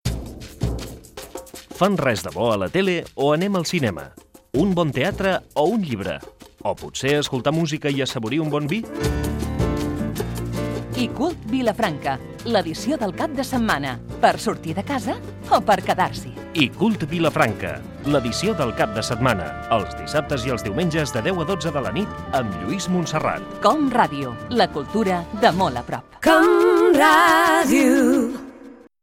Promoció del programa
Banda FM